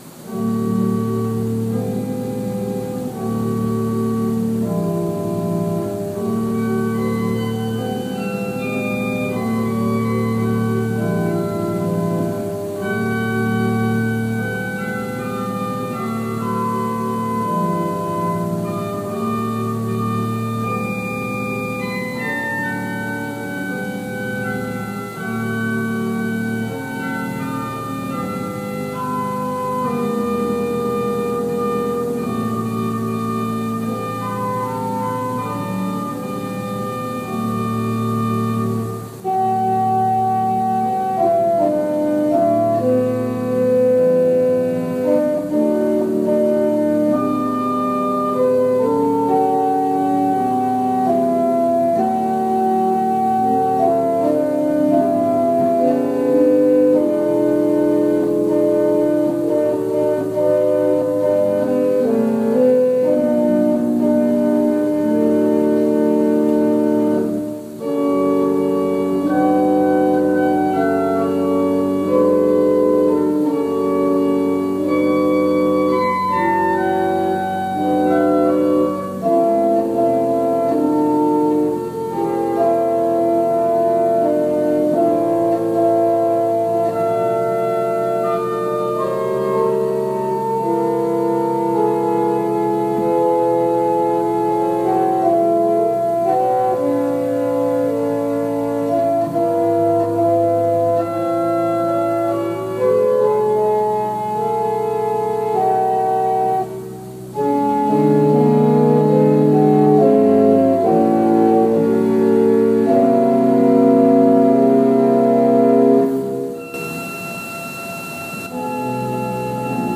Worship Service February 21, 2021 | First Baptist Church, Malden, Massachusetts
Prelude: “Deep River” by David H. Hegarty, based on the traditional Spiritual